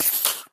sounds / mob / creeper